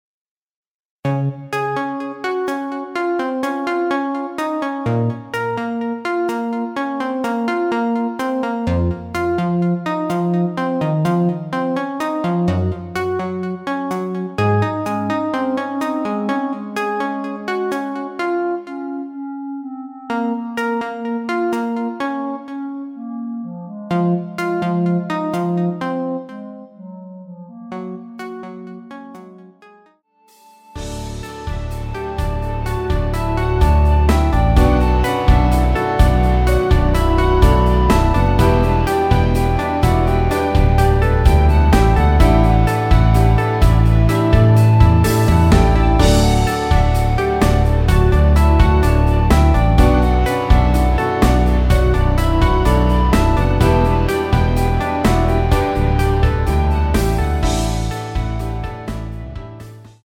(-2) 내린 멜로디 포함된 MR 입니다.
Db
앞부분30초, 뒷부분30초씩 편집해서 올려 드리고 있습니다.
중간에 음이 끈어지고 다시 나오는 이유는